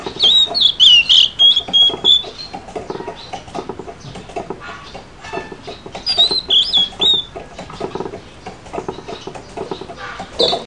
さえずりのコーナー（オオルリ編）
幼鳥から 105KB 今年春、成鳥に近づきさえずりも変わってきました